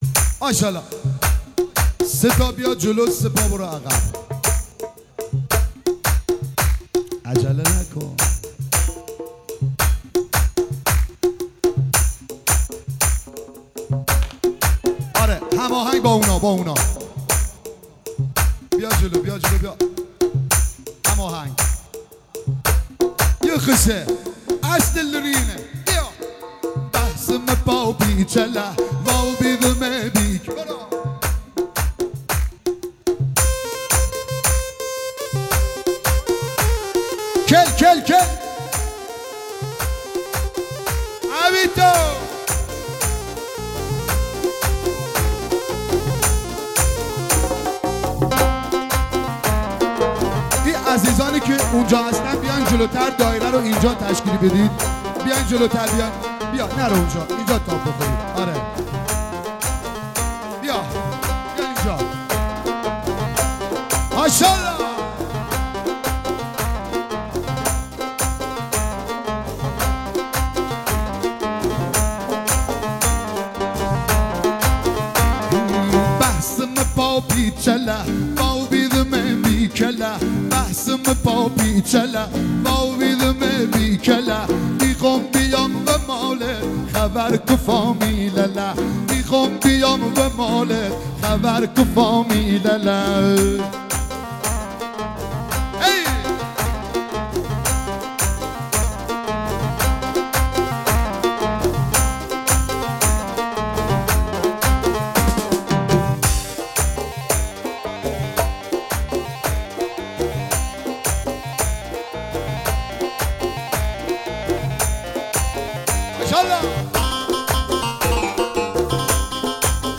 ریمیکس لری